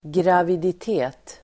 Uttal: [gravidit'e:t]